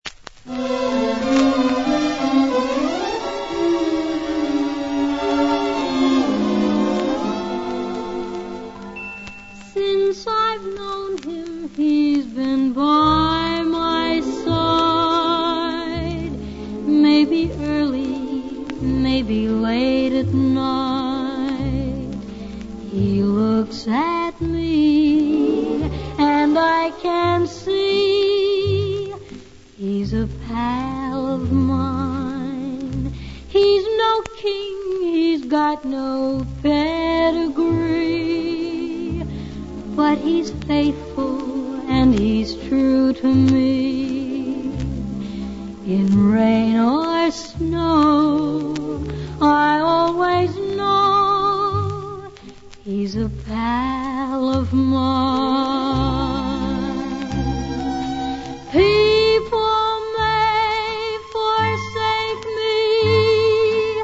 (vinyl)